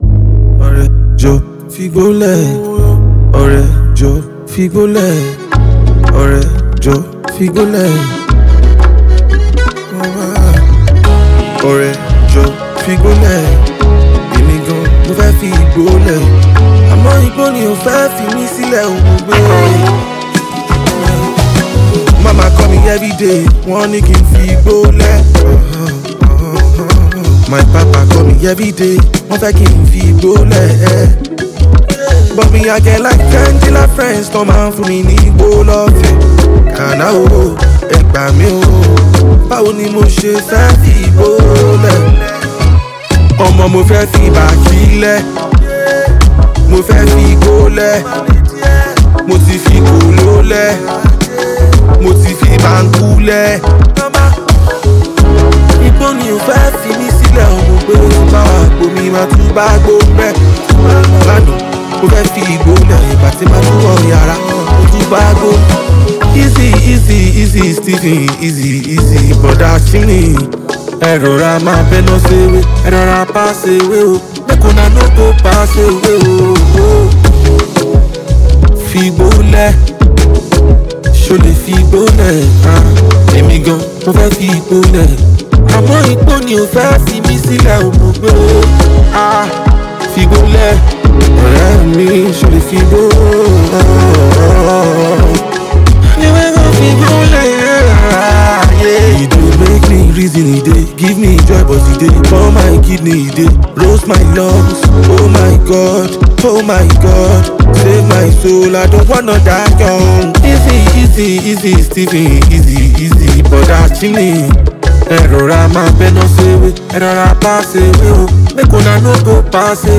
Talented Nigerian hip-hop sensation